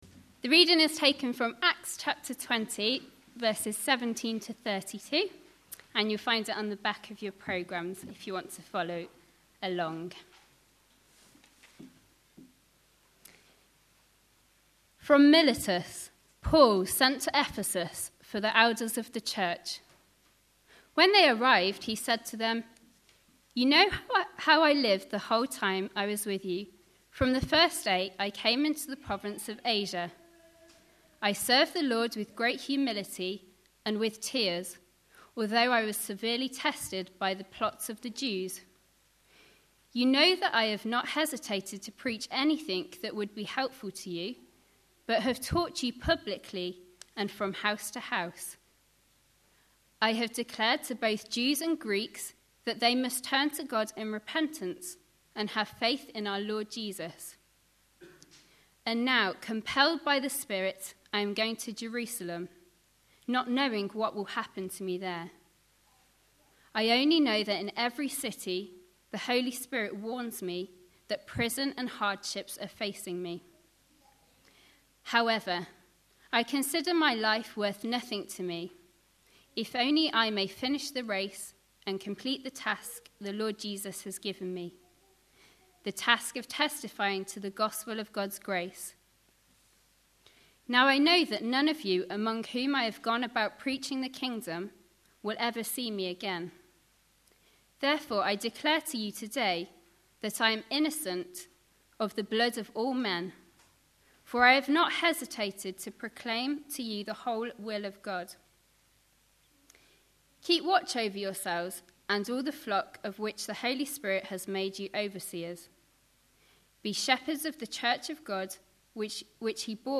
Trinity Church Bradford Launch Event
A sermon preached on 14th May, 2011.
Acts 20:17-32 Listen online Details We apologise for the poor recording of this talk.